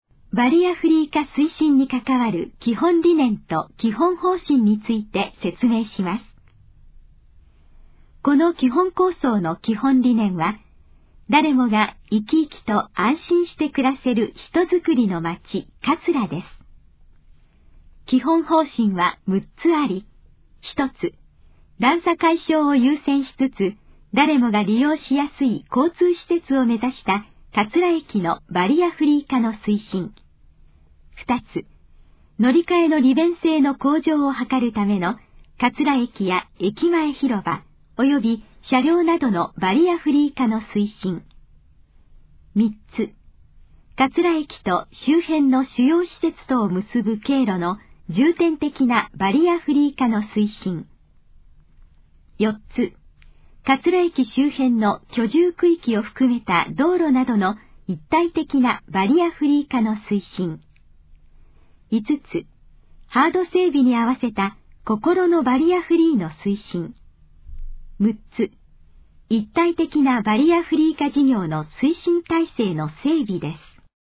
このページの要約を音声で読み上げます。
ナレーション再生 約149KB